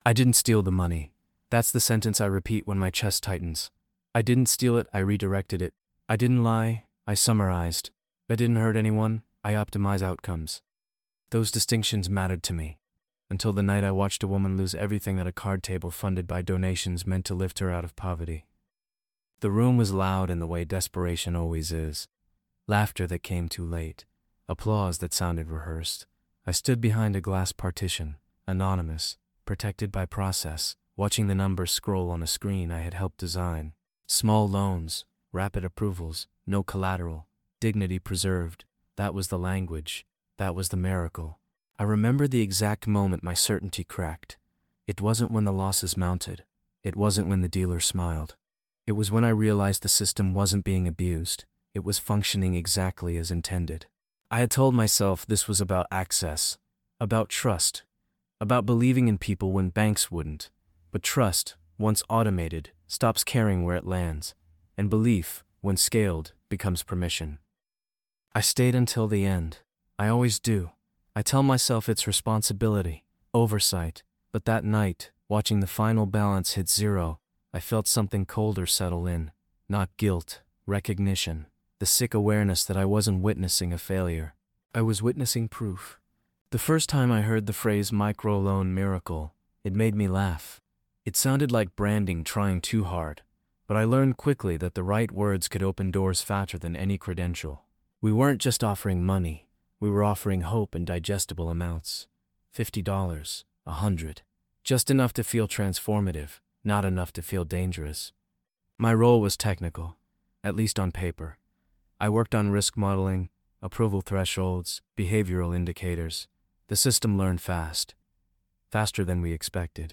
The Micro-Loan Miracle follows a man who believes he is helping lift people out of poverty—until he realizes the system he helped design is working exactly as intended. Through first-person narration, the episode traces how charitable micro-loans, celebrated as acts of dignity and empowerment, quietly funnel into gambling and loss, not through abuse, but through design.